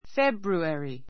February 小 A1 fébjueri ふェ ビュエリ fébrueri ふェ ブ ルエリ ｜ fébruəri ふェ ブ ルアリ 名詞 2月 ⦣ Feb.